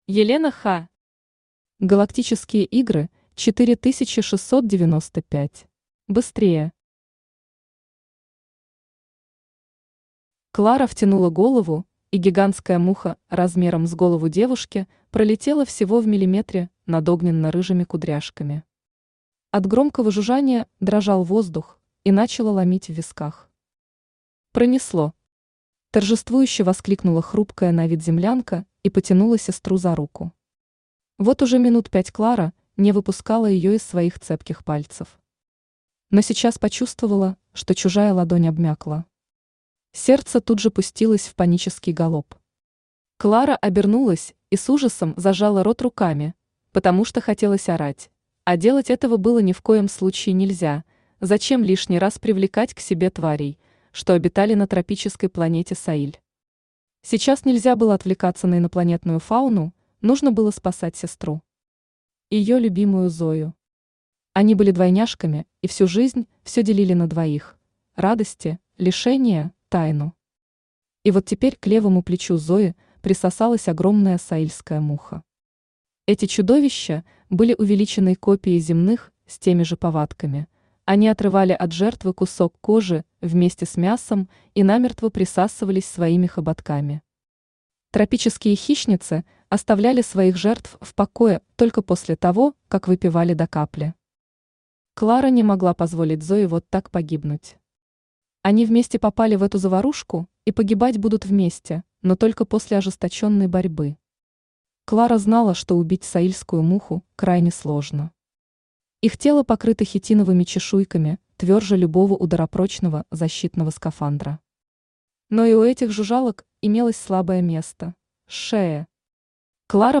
Аудиокнига Галактические игры – 4695 | Библиотека аудиокниг
Aудиокнига Галактические игры – 4695 Автор Елена Ха Читает аудиокнигу Авточтец ЛитРес.